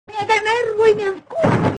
Worms speechbanks
goaway.wav